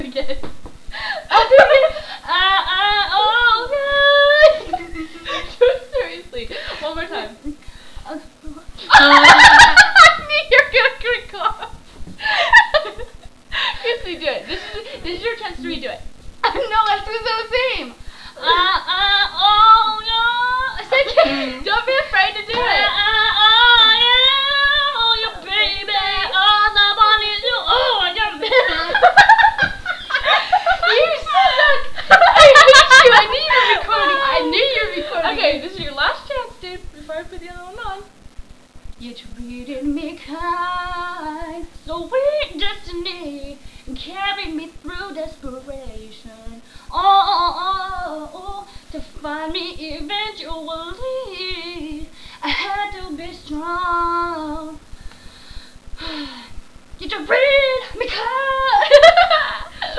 She's singing, making fun of herself holding back.(Not serious at first) but then she starts to sing for real but then goofs up again at the end.